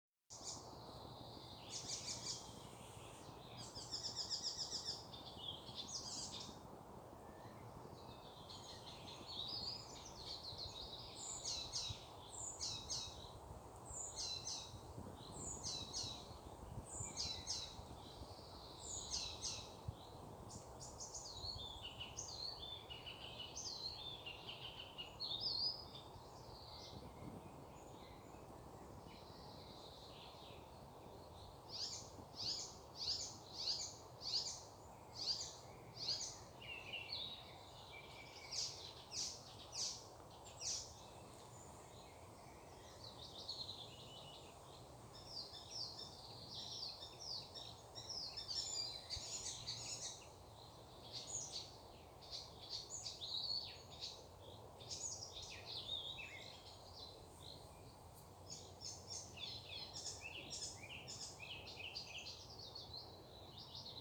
Птицы -> Славковые ->
пересмешка, Hippolais icterina
Administratīvā teritorijaRīga
СтатусПоёт